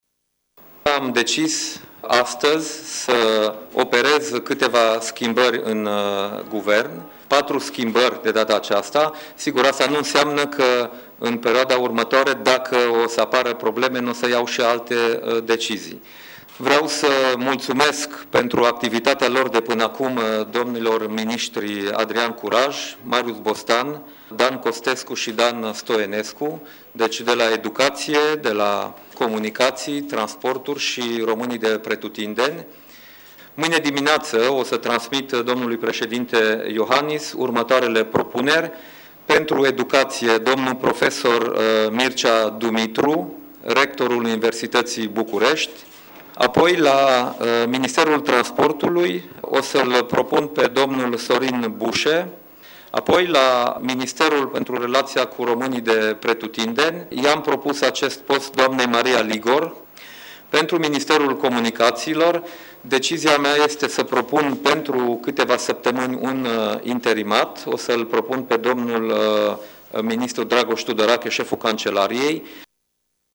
Premierul Dacian Cioloș: